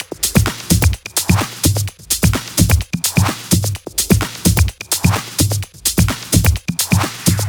VFH1 128BPM Big Tee Kit 4.wav